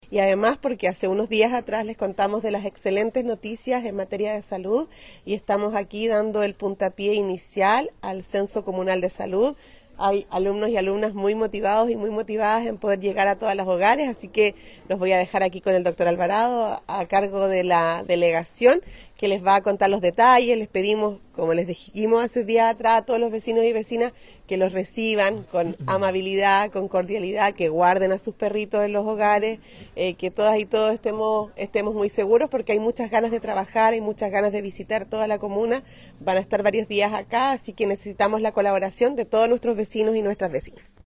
Al respecto, la jefa comunal curacana explicó que se trata de un inédito despliegue en terreno que cuenta con el apoyo logístico del Cesfam y la Municipalidad; y en la alimentación Junaeb y la empresa Salmones Antártica, el que está inserto en una alianza con la casa de estudios porteña mediante sus alumnos de la asignatura electiva sobre investigación en salud comunitaria y diagnóstico de salud familiar:
1-ALCALDESA-CENSO-.mp3